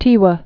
(tēwə)